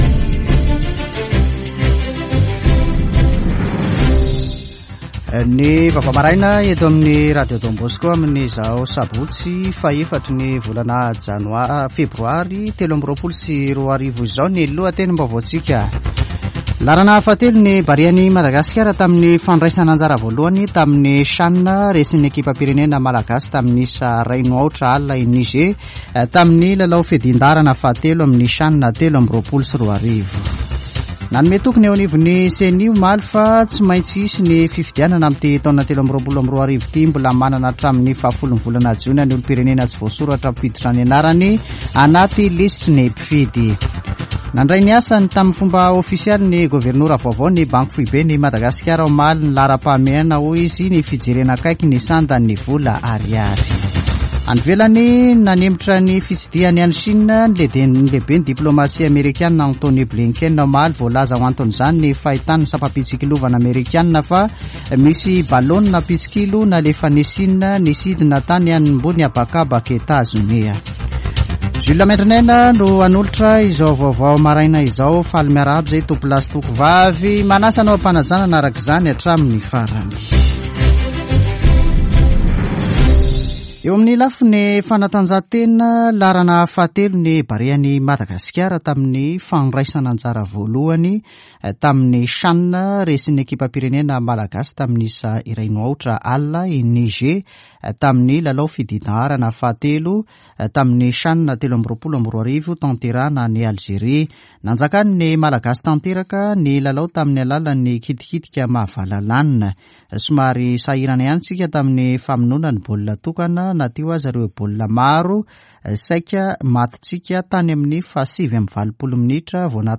[Vaovao maraina] Sabotsy 04 febroary 2023